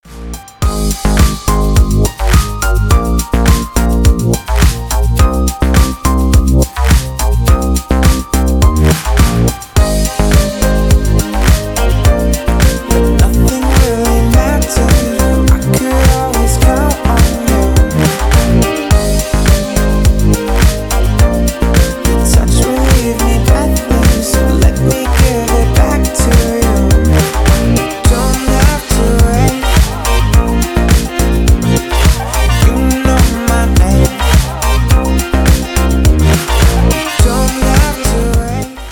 • Качество: Хорошее
• Песня: Рингтон, нарезка
играет Спокойные звонки, спокойные рингтоны🎙